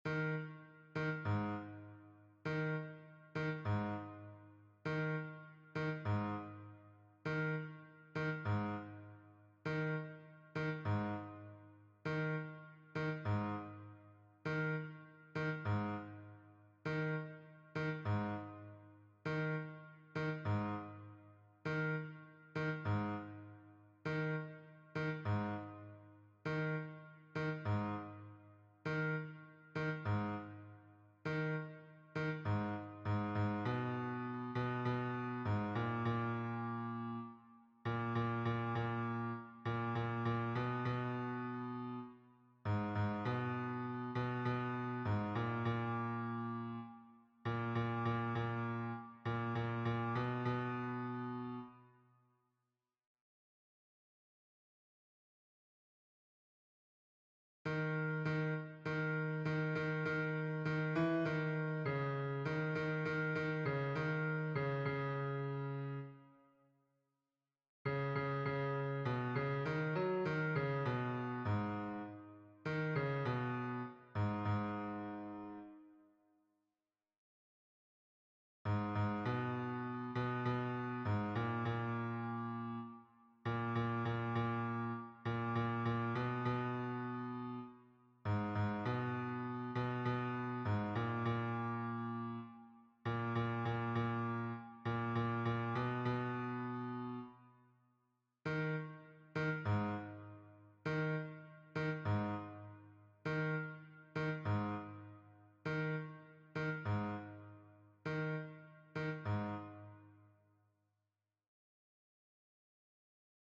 - berceuses juive séfarade
MP3 version piano
hommes piano